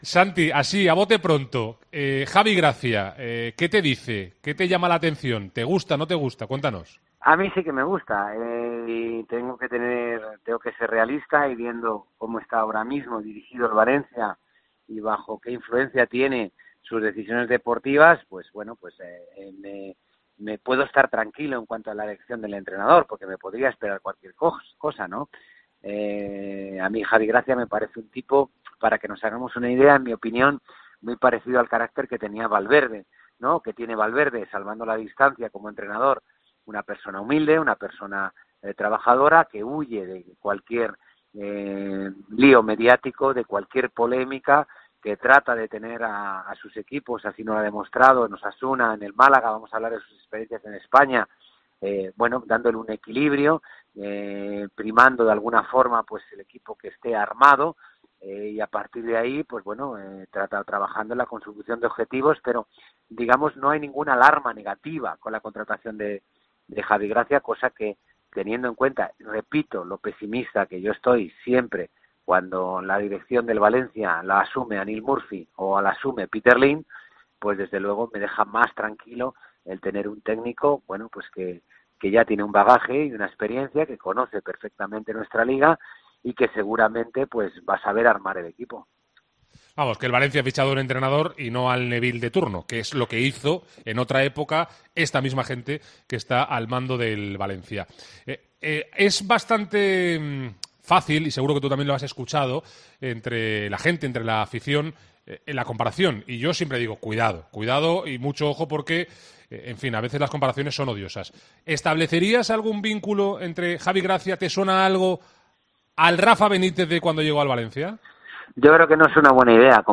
El ex guardameta del Valencia CF comenta en Deportes COPE Valencia la elección del entrenador y se muestra muy crítico con la gestión del club